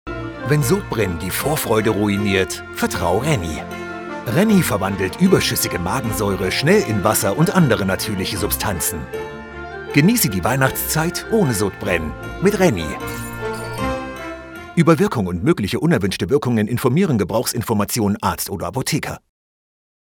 Radio Imaging
I sound dynamic, fresh, powerful, cool and confident and I’m ready to record in my studio 24/7 – also via remote control: Source Connect Now, Session Link Pro, ipDTL, Skype, Microsoft Teams.
microphone: Neumann TLM 49